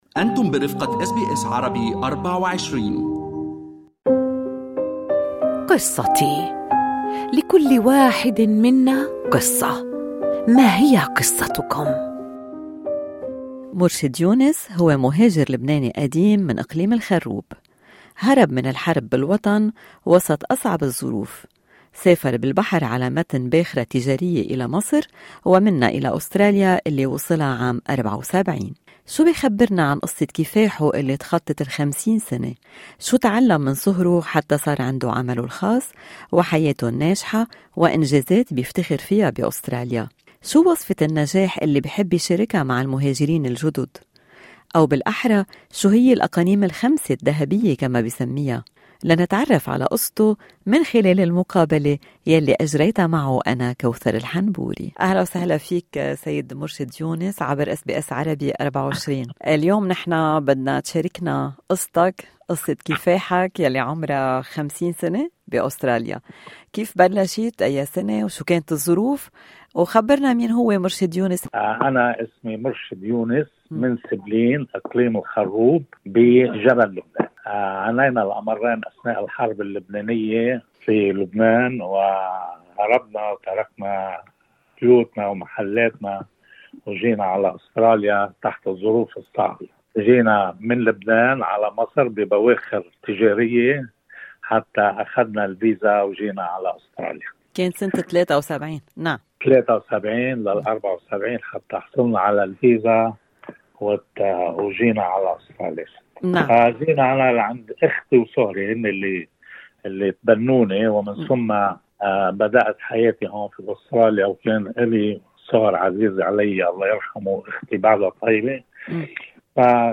ما هي المعايير الخمسة الذهبية للنجاح في أستراليا؟ مهاجر لبناني يشرح